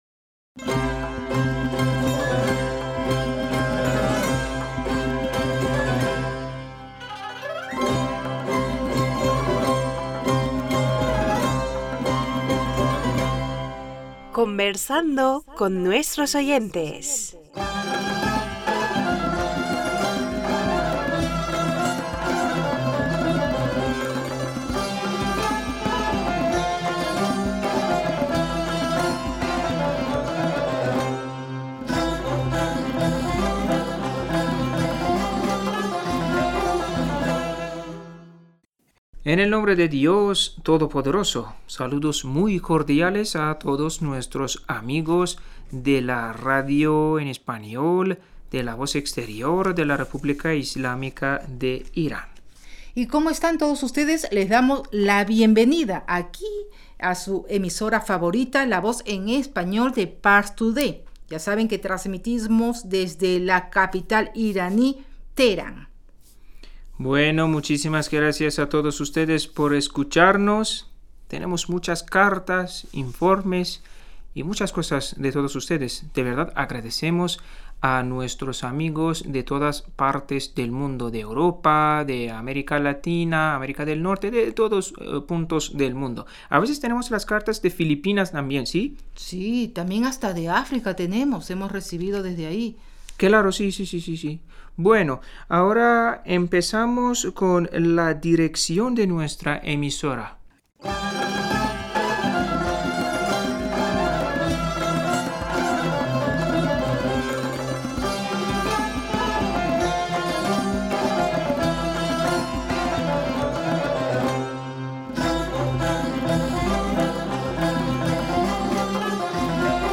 Pars Today-Las entrevistas, leer cartas y correos de los oyentes de la Voz Exterior de la R.I.I. en español.